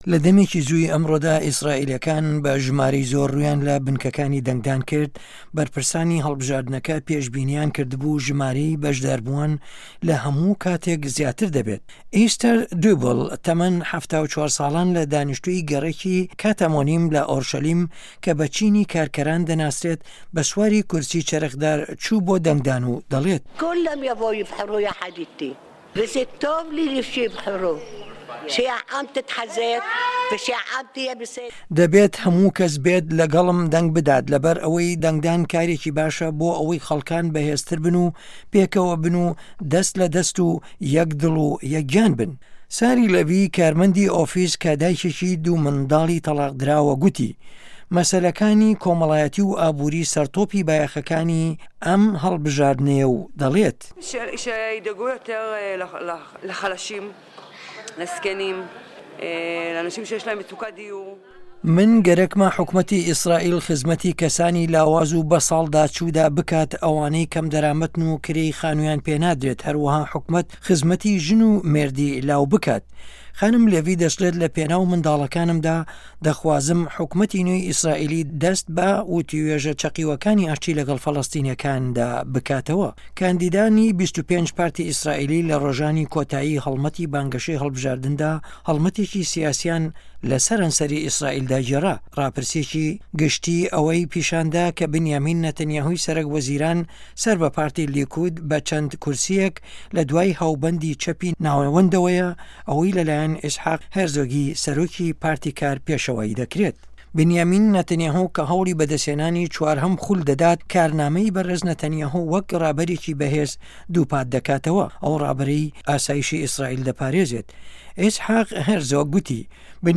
راپۆرتی ئیسرائیل